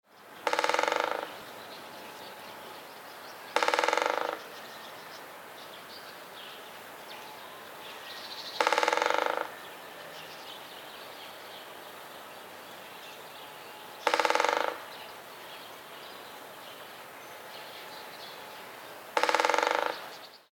März: Buntspecht (Dendrocopos major)
0183_Buntspecht_Trommeln.mp3